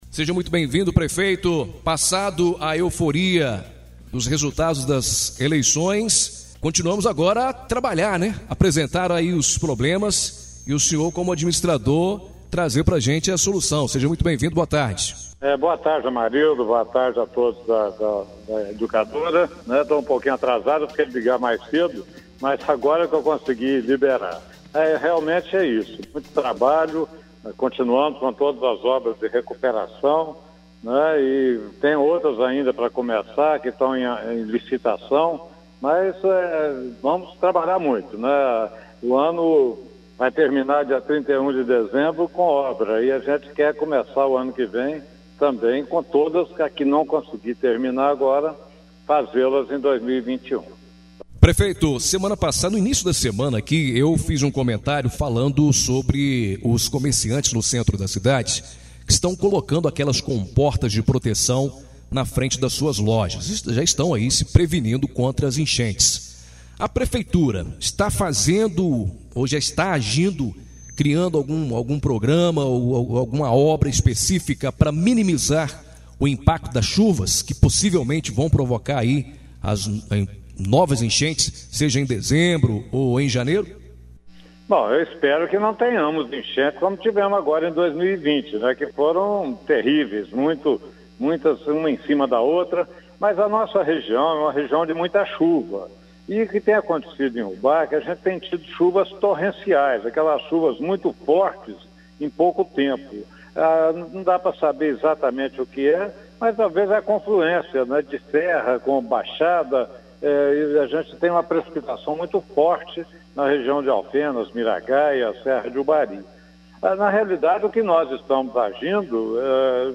Entrevista exibida na Rádio Educadora AM/FM Ubá-MG